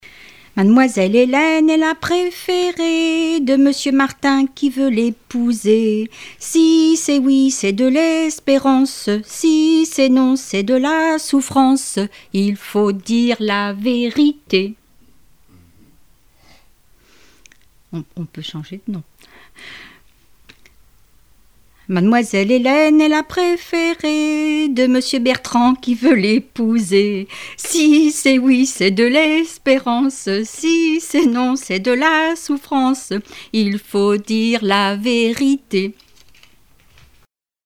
Mémoires et Patrimoines vivants - RaddO est une base de données d'archives iconographiques et sonores.
Localisation Saint-Gilles-Croix-de-Vie
Enfantines - rondes et jeux
Répertoire de chansons populaires et traditionnelles